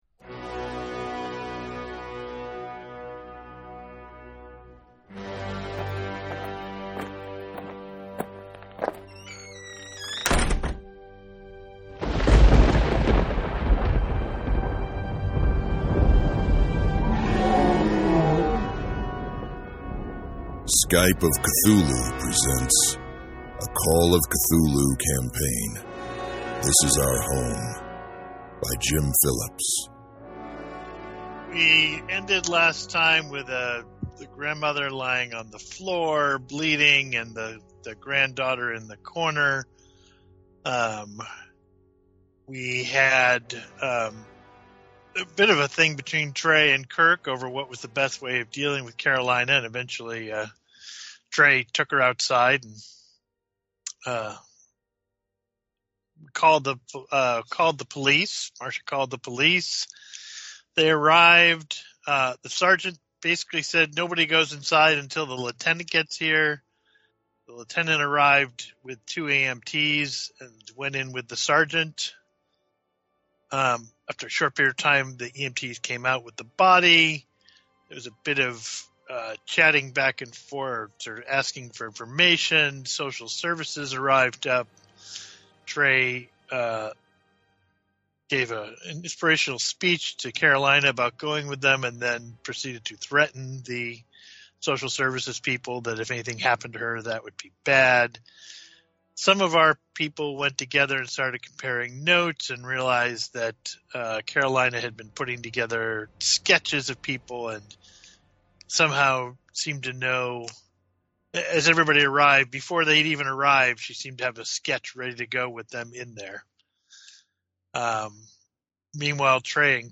Skype of Cthulhu presents a Call of Cthulhu scenario.